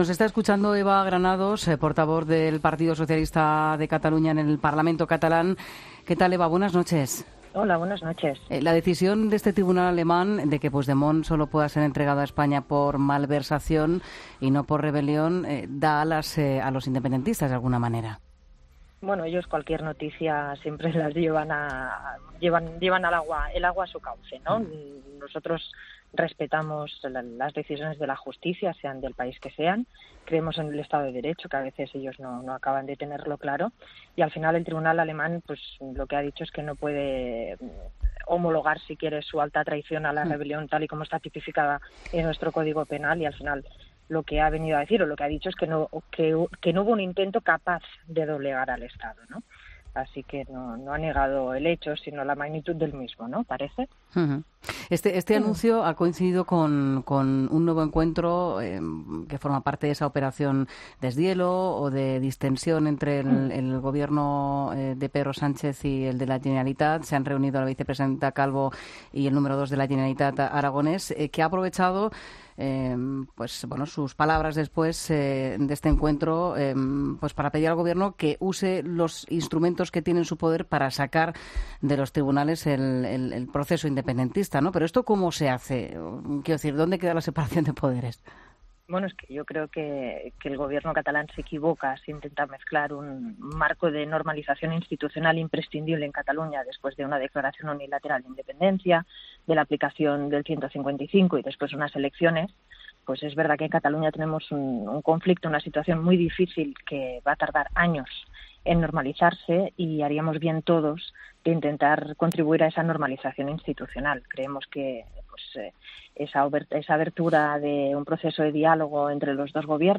La portavoz del PSC en el Parlament, Eva Granados, se ha pronunciado al respecto en ' La Linterna ' de la Cadena COPE.